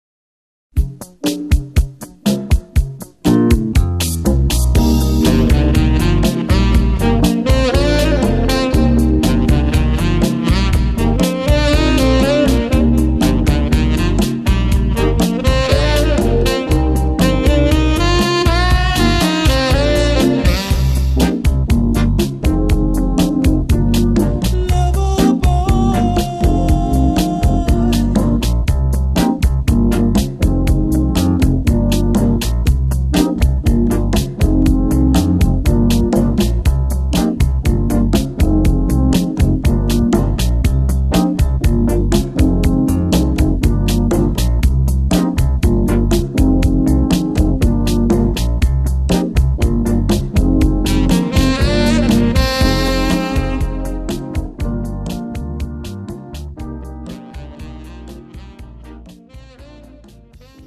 음정 (-2키)
장르 pop 구분